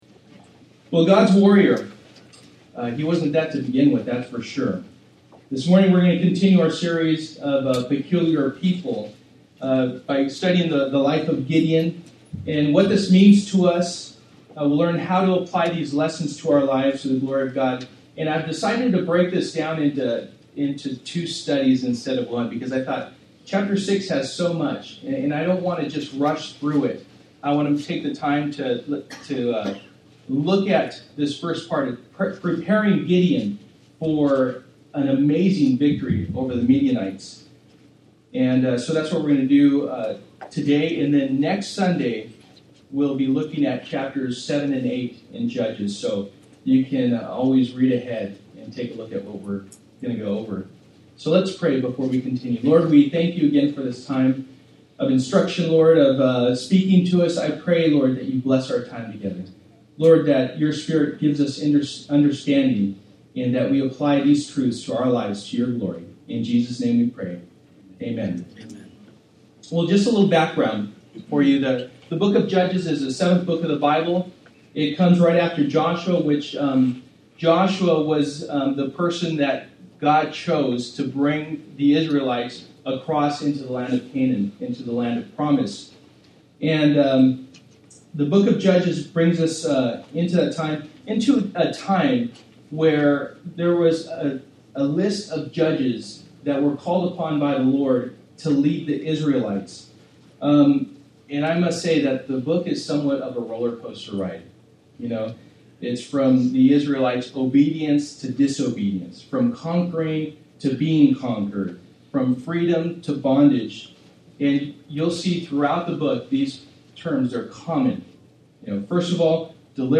Passage: Judges 6:1-8:35 Service: Sunday Morning